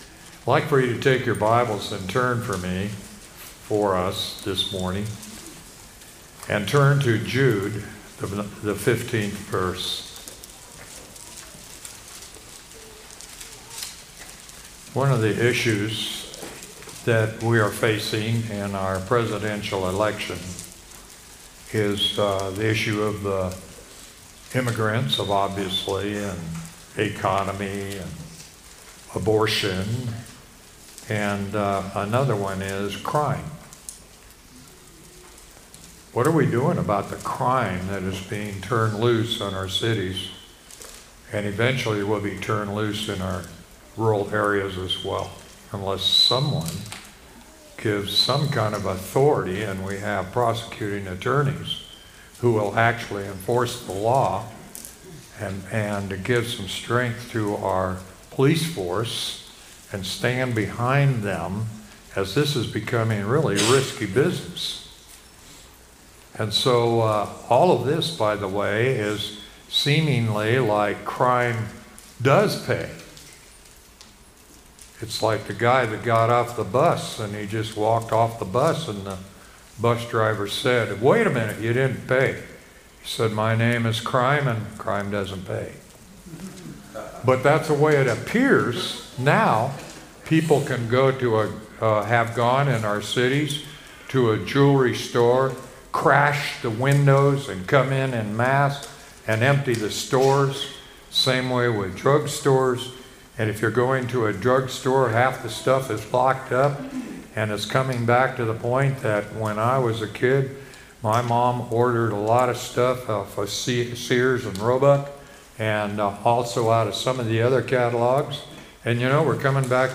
sermon-9-8-24.mp3